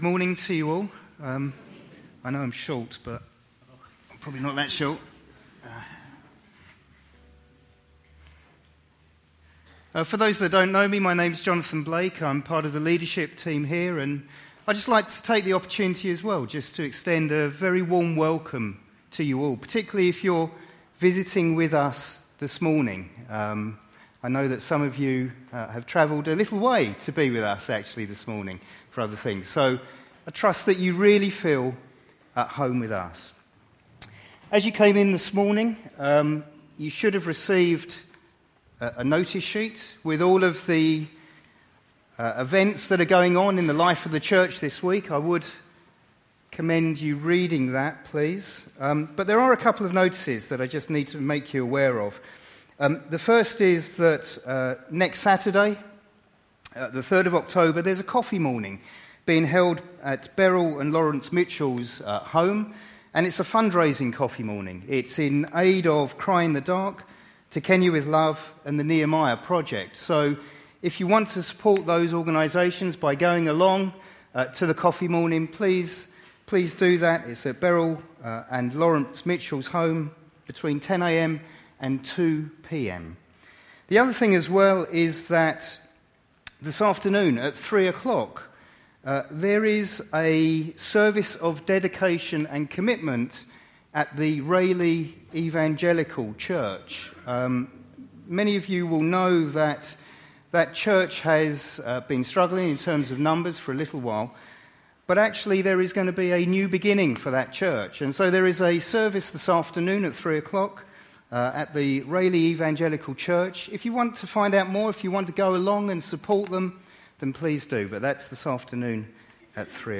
A message from the series "Acts."